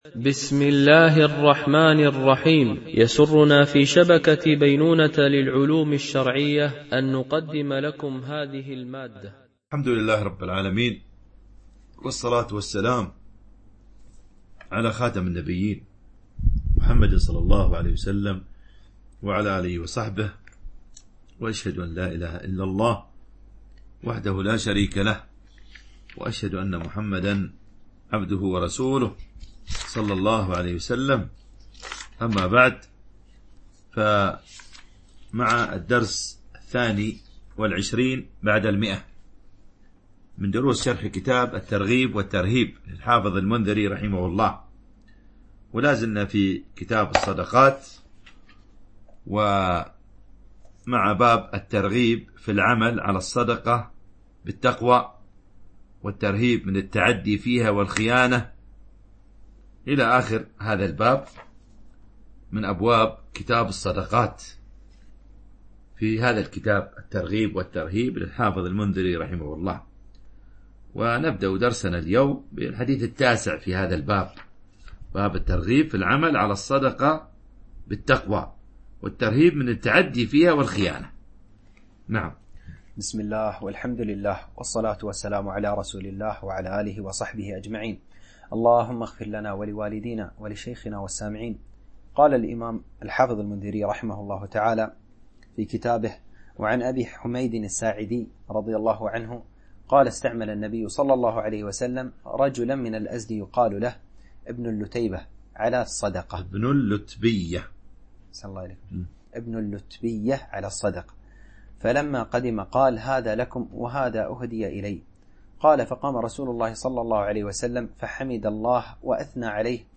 شرح كتاب الترغيب والترهيب - الدرس 122 ( كتاب الصدقات - باب الترغيب في العمل على الصدقات بالتقوى ... )